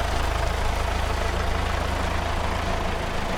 stardew-valley-mods/TractorMod/assets/audio/idle.ogg at main
idle.ogg